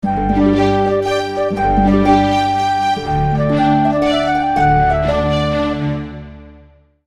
Mind stílusban, mind hangulatilag igazodnak az eredetiekhez.